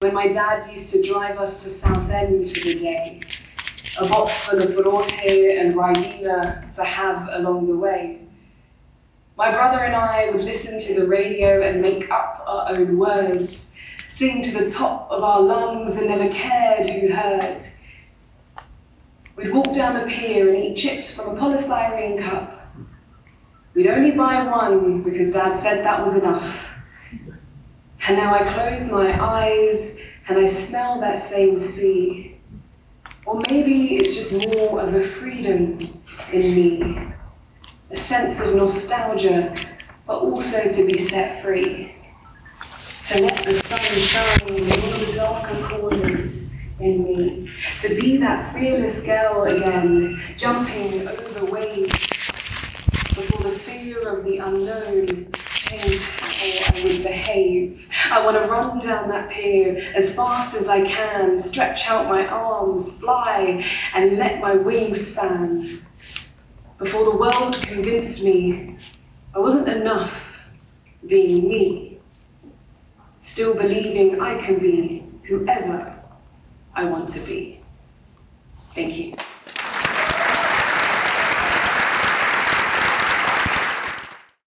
Listen to the Spoken Word below (and excuse us for the slight background noise).
Spoken Word recorded at the MSDUK Conference 2022, and published with permission